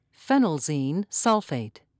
(fen'el-zeen)